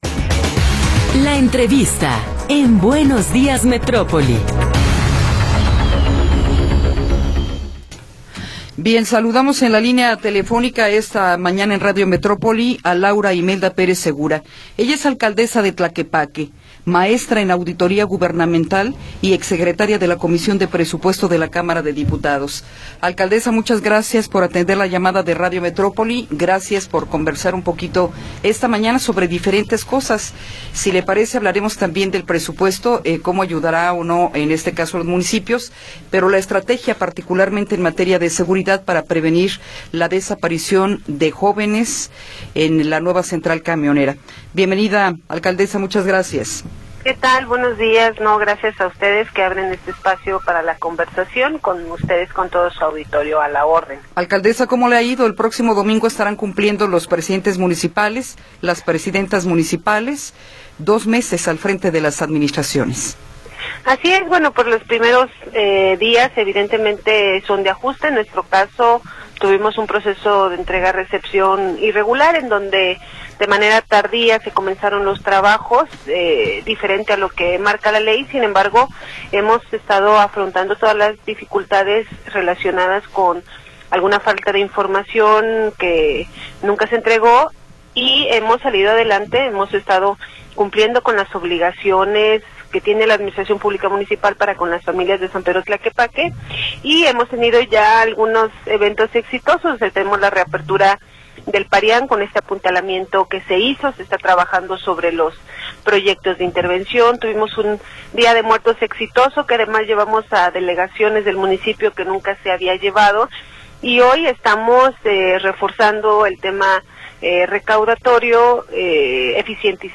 Entrevista con Laura Imelda Pérez Segura
Laura Imelda Pérez Segura, alcaldesa de Tlaquepaque, maestra en auditoría gubernamental, nos habla sobre el presupuesto para el año que entra y la estrategia de seguridad en ese municipio.